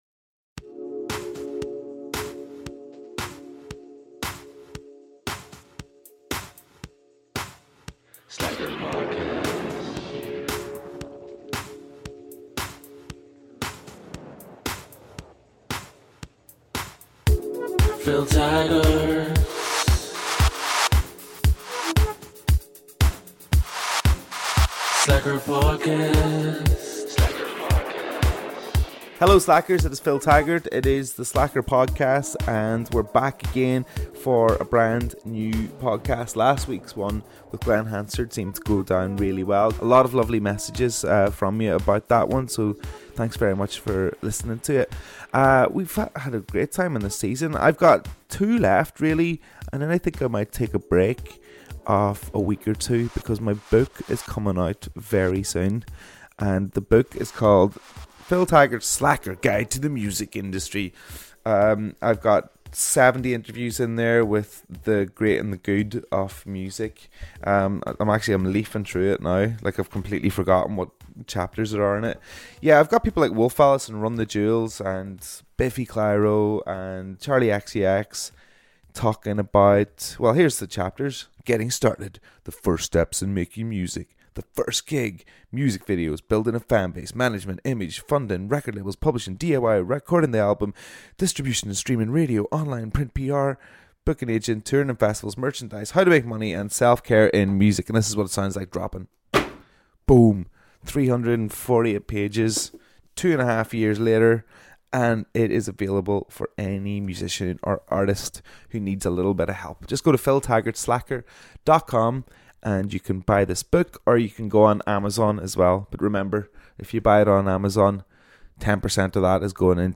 The podcast that unearths the artists early demo's is back for another week. On our next episode of Season 2 our guest is one of the most influential guitarists of the last 50 years.